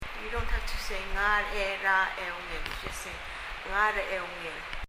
別の例では、 er + a のほぼ全体が音として消える、というケースもあるようです。
発音　だそうです。
PronounceEra.mp3